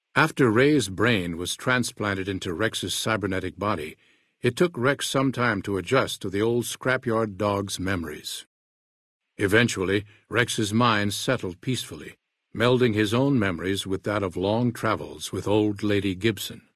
New Vegas endgame narrations Du kannst diese Datei nicht überschreiben. Dateiverwendung Die folgende Seite verwendet diese Datei: Enden (Fallout: New Vegas) Metadaten Diese Datei enthält weitere Informationen, die in der Regel von der Digitalkamera oder dem verwendeten Scanner stammen.